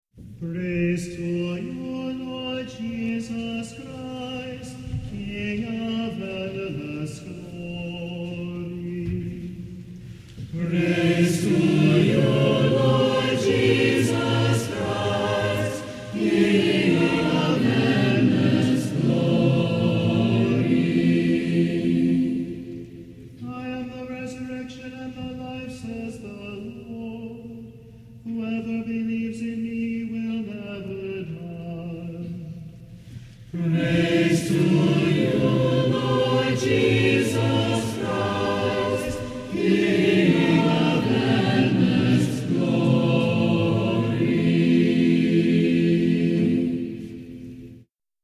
(GOSPEL ACCLAMATION during Lent)
(two SATB harmonizations)
4353 (audio mp3 file) Sung by the Wyoming Catholic College Choir